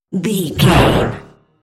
Sci fi electronic whoosh
Sound Effects
futuristic
high tech
whoosh